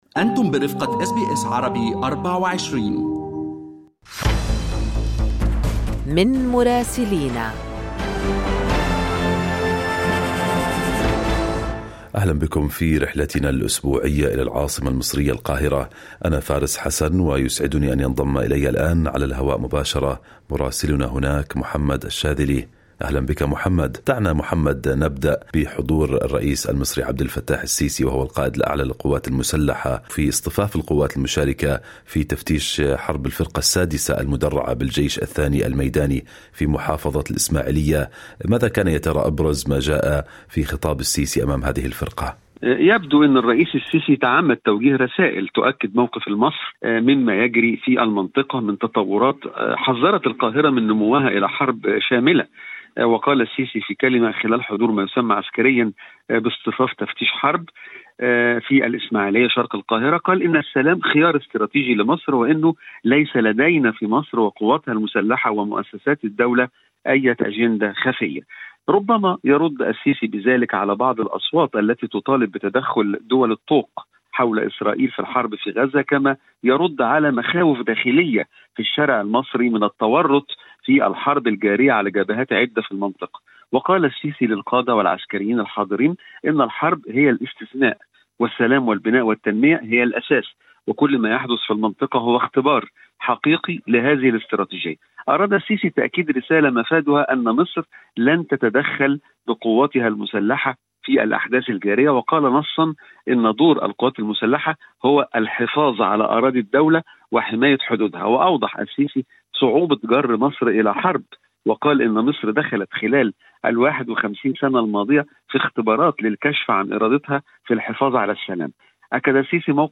استمعوا إلى تقرير مراسلنا في القاهرة